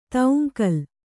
♪ tauŋkal